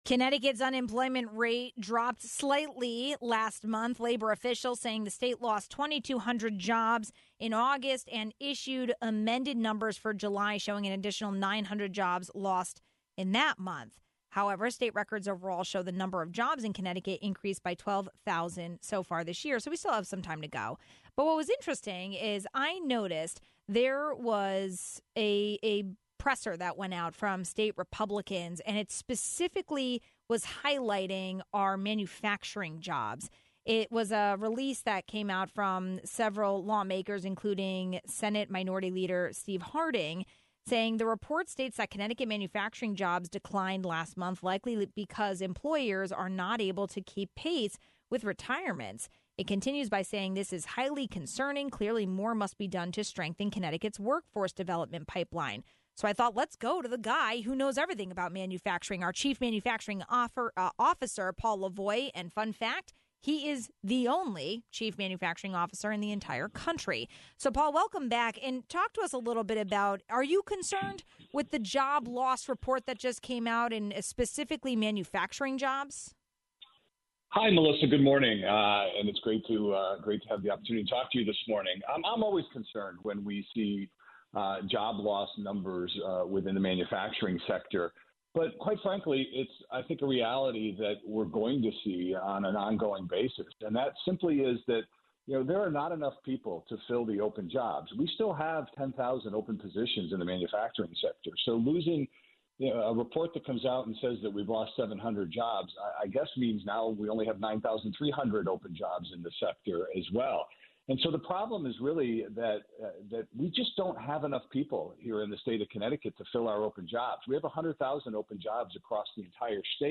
We spoke to Connecticut’s Chief Manufacturing Officer, Paul Lavoie, to get a better understanding of the challenges filling manufacturing positions and how the state is tackling the problem.